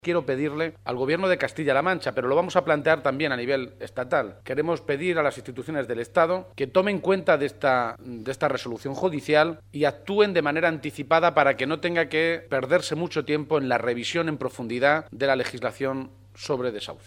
Se ha pronunciado de esta manera en una comparecencia ante los medios de comunicación en Guadalajara, poco antes de mantener una reunión con alcaldes y concejales socialistas de toda la provincia.
Cortes de audio de la rueda de prensa